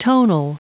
Prononciation du mot tonal en anglais (fichier audio)
Prononciation du mot : tonal